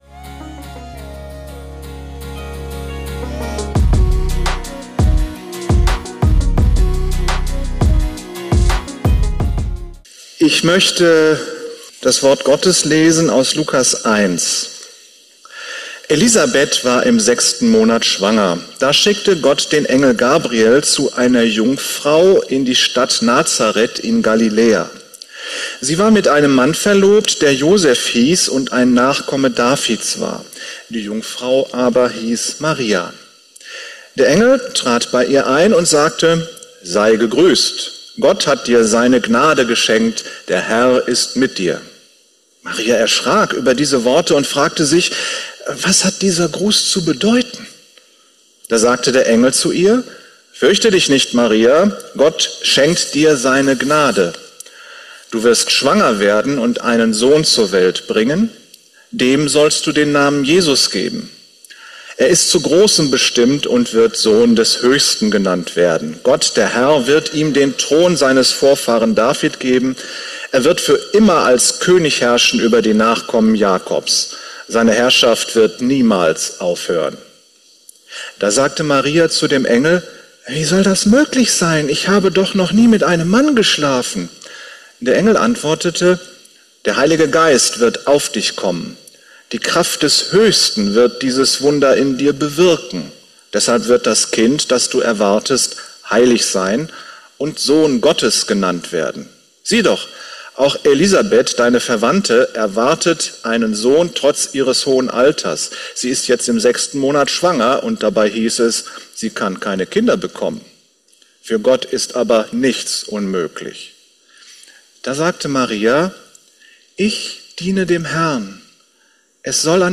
In einer Predigt Pause wird Musik eingespielt.